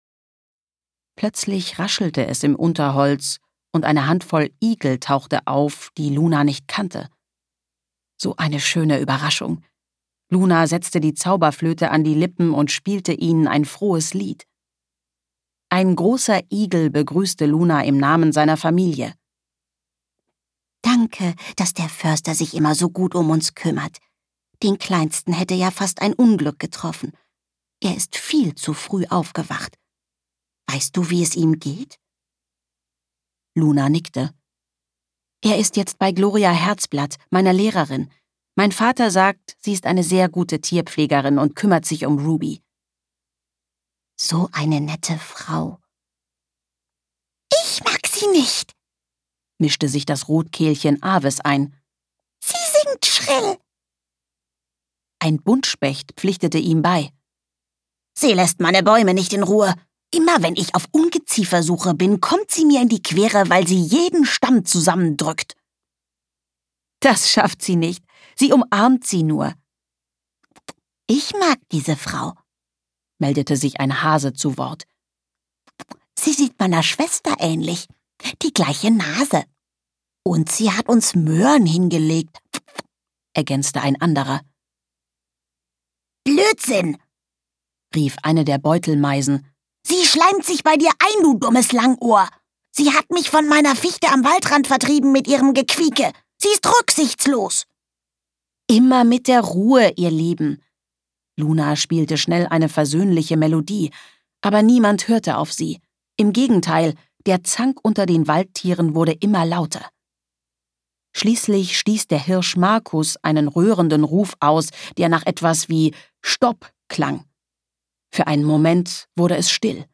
Kinderhörbuch